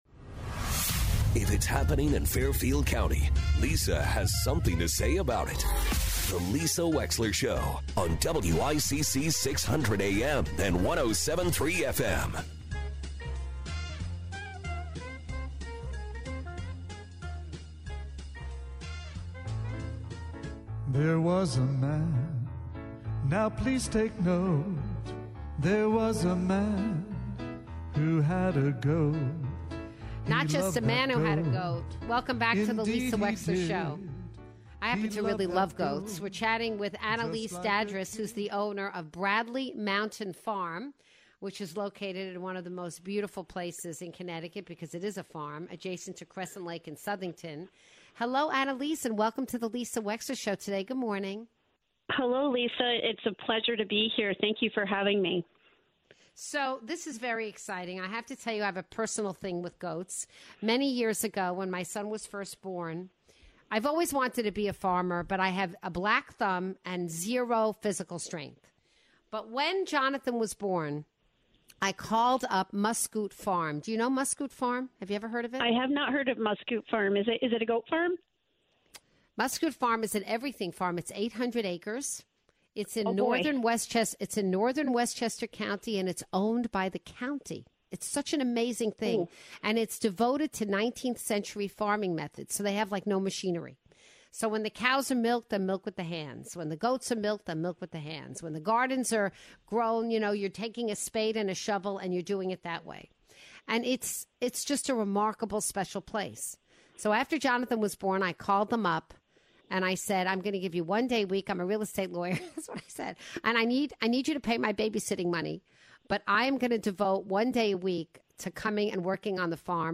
calls in to talk about the joy of goats and how you can get involved in their farm!